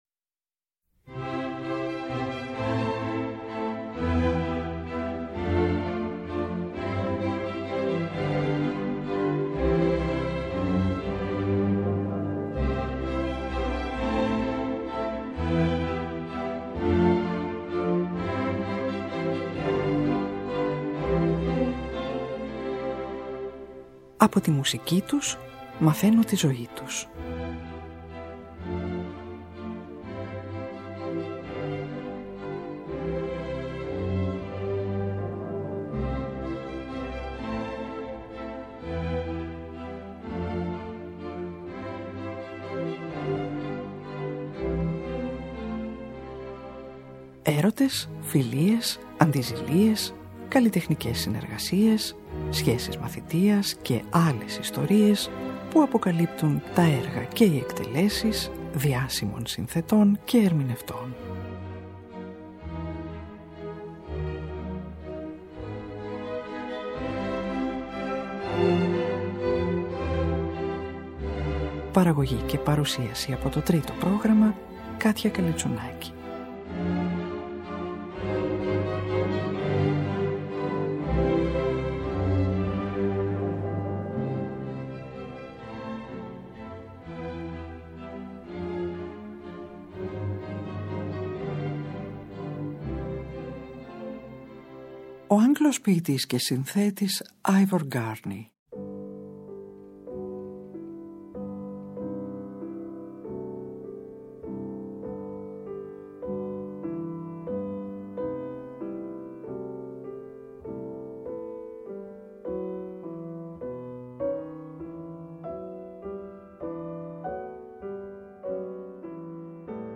Από τη συνθετική του δραστηριότητα την περίοδο 1914-1921 ακούγονται 9 τραγούδια σε ποιήματα των William Shakespeare, John Fletcher, John Masefield, William Butler Yeats, Walter de la Mare, Joseph Campbell και σε βιωματικά ποιήματα του ίδιου και του στενού του φίλου Frederick William Harvey, ένα Πρελούδιo για Πιάνο και η ορχηστρική Ραψωδία του Gloucestershire, μουσικό εγκώμιο του γενέθλιου τόπου του στον οποίο κηδεύθηκε το 1937.